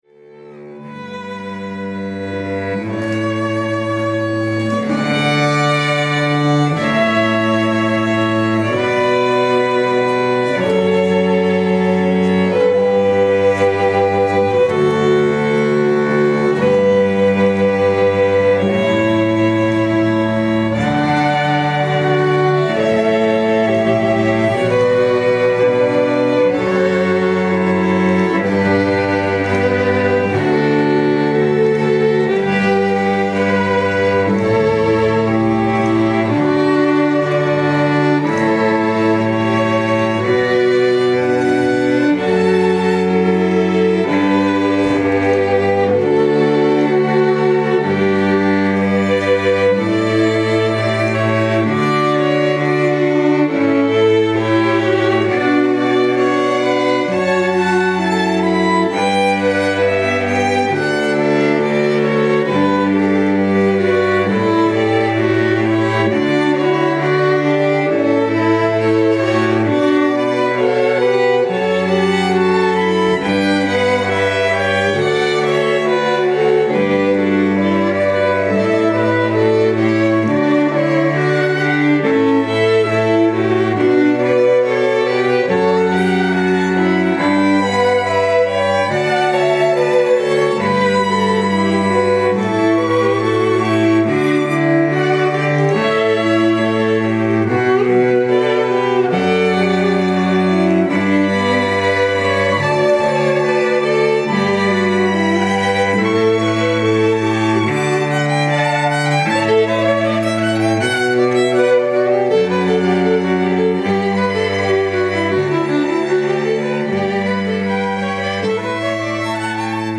The Hills Quartet are a professional ensemble with exquisite string arrangements of classical and contemporary music.
West Midlands Based String Quartet